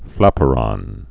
(flăpə-rŏn)